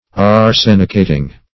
Search Result for " arsenicating" : The Collaborative International Dictionary of English v.0.48: Arsenicate \Ar*sen"i*cate\, v. t. [imp.